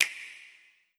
Nintendo Switch Startup.wav